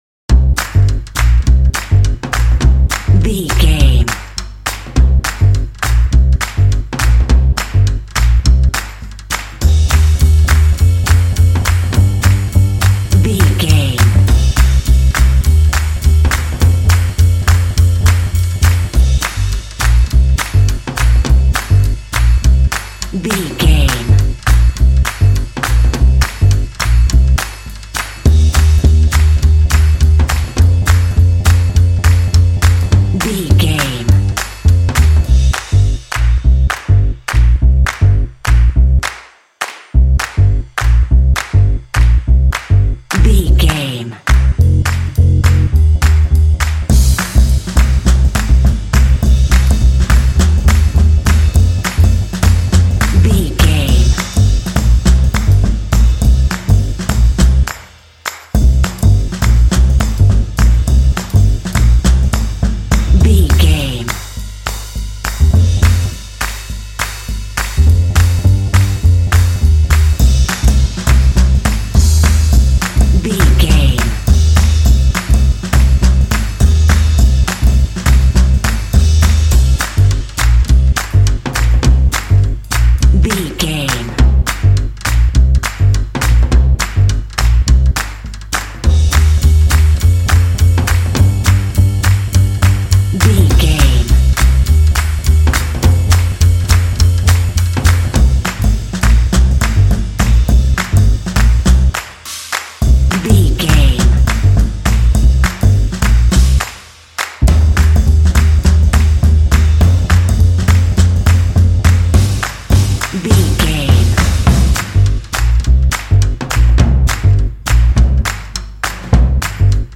Aeolian/Minor
confident
determined
lively
drums
bass guitar
jazz